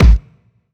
GS Phat Kicks 020.wav